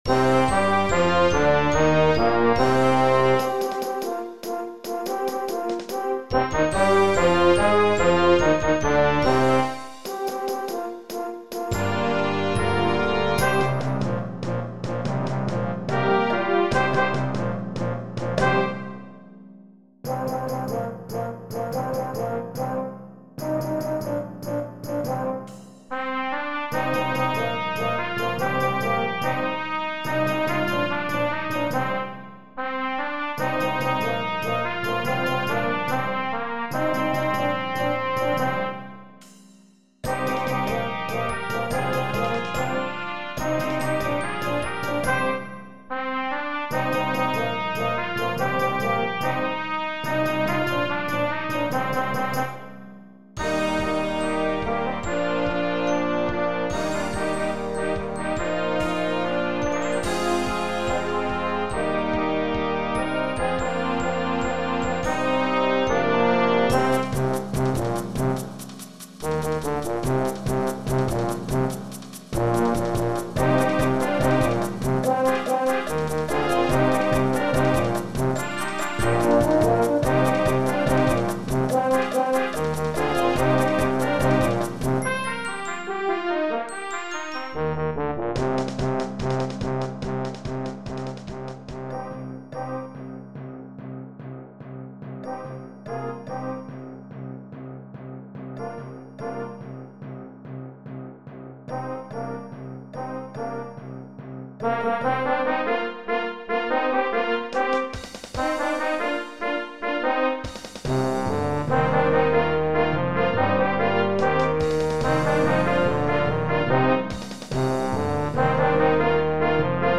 Voicing: Brass Octet w/ Percussion